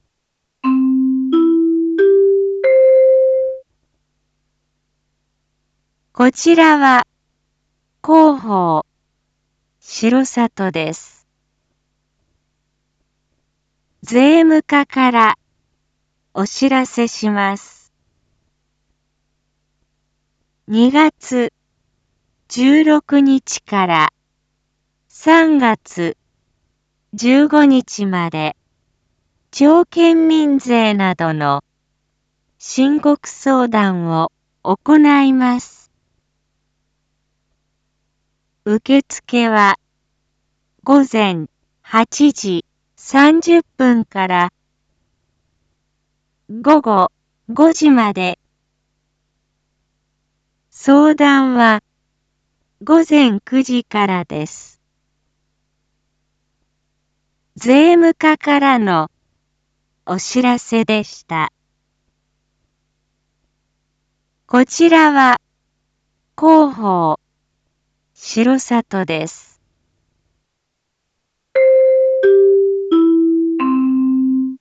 Back Home 一般放送情報 音声放送 再生 一般放送情報 登録日時：2022-03-14 07:01:15 タイトル：R4.3.14 7時 放送分 インフォメーション：こちらは広報しろさとです。